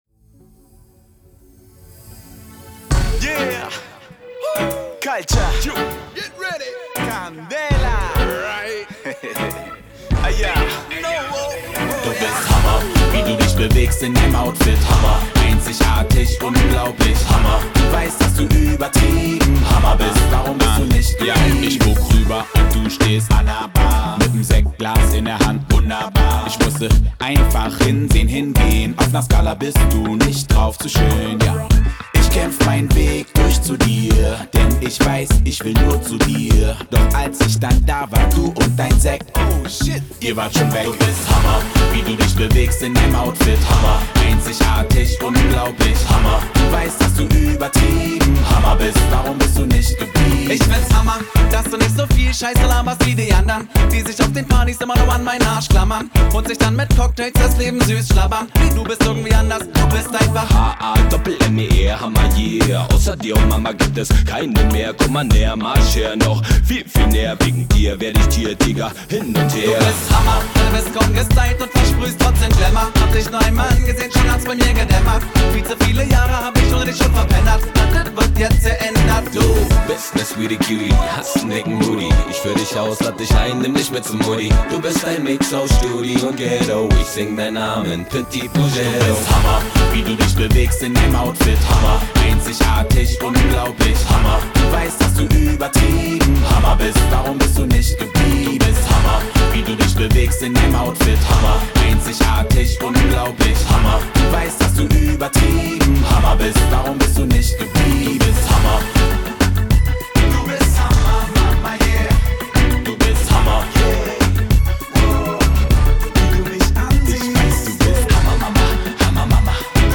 Hip Hop GER